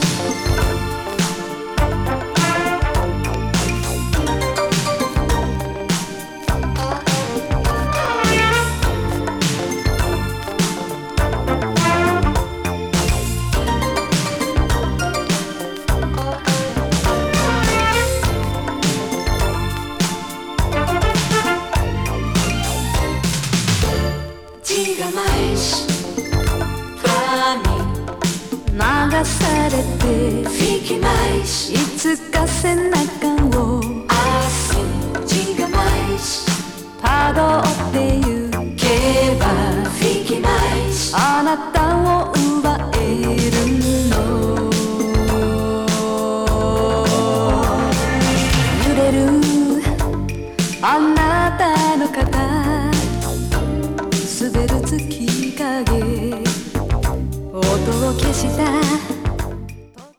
洗練＆抑制されたミッドテンポのグルーヴが最高な、極上アーバン・メロウ・ソウル！